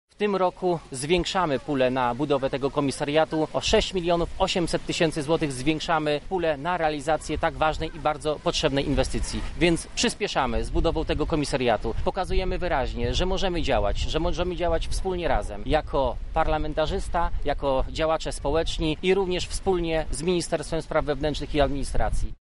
Sylwester Tułajew, kandydat na prezydenta Lublina, pochwalił się swoim wkładem w budowę komisariatu i mówi dlaczego może on zostać oddany do użytku wcześniej.